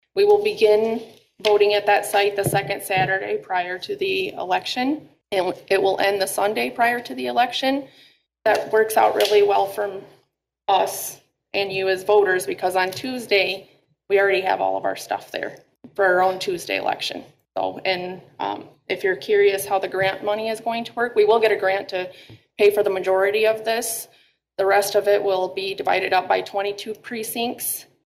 City Clerk Shauna Chávez told the Council early voting in the State of Michigan is taking place after voters approved Proposal Two in 2022.